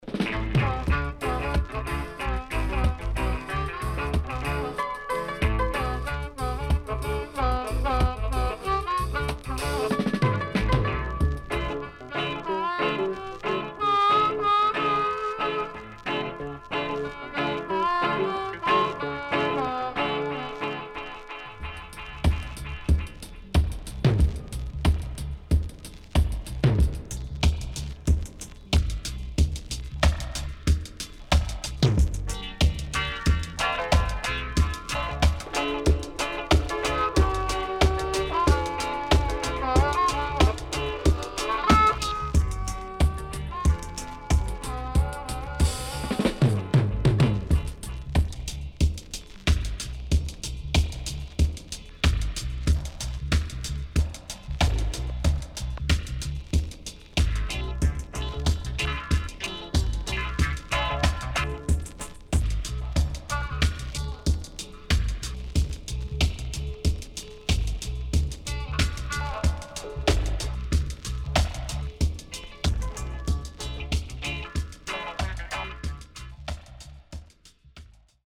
HOME > DUB
SIDE A:全体的にチリノイズが入ります。